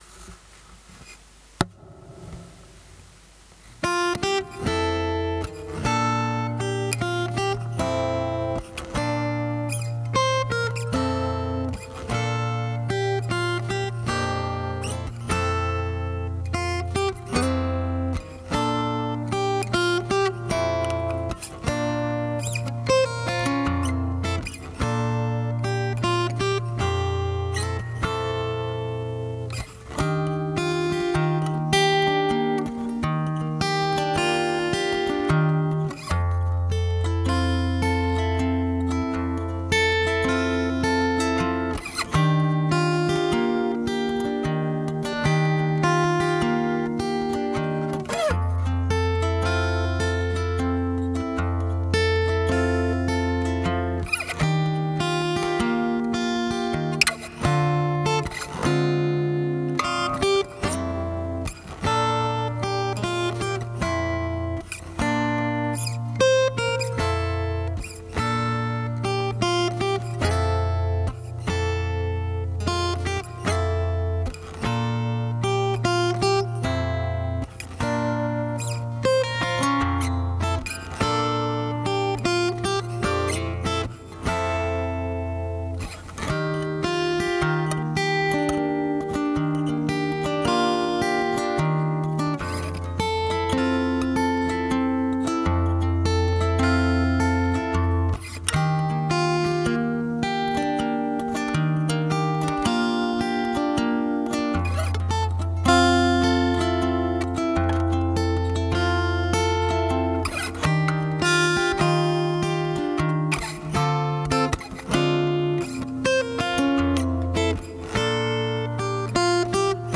guitar
Diving deep into the healing vibrations of sound and form we have created half a dozen musical meditations (so far) to help disconnect from the consciousness of the mass,  Spring clean your energetic field and relax into the wonderful feeling of Presence  .